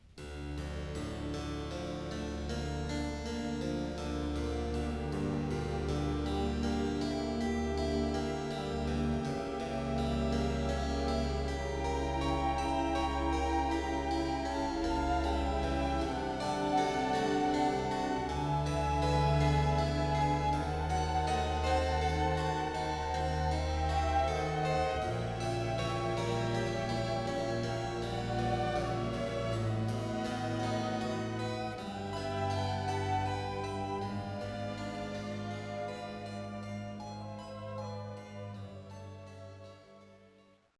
It was left to the skill of the harpsichord player to interpret the figures, whilst the bass notes themselves were performed by the cellos and basses.